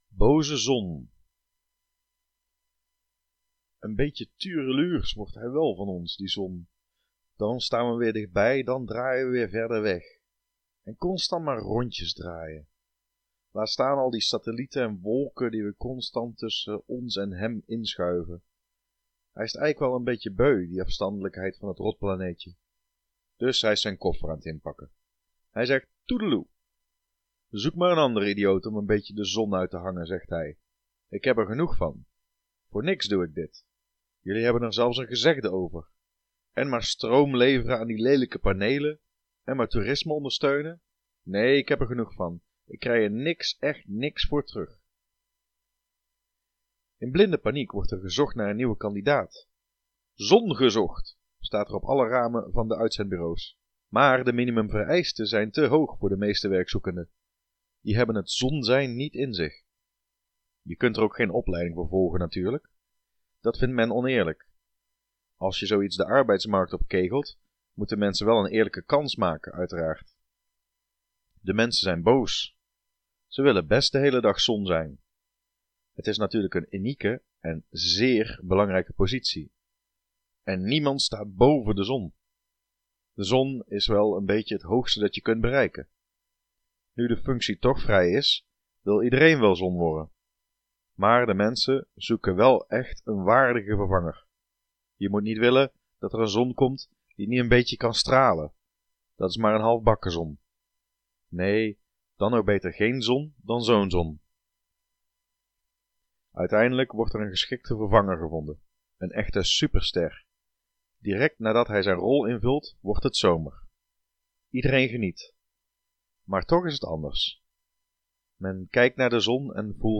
Audio stories Korte proza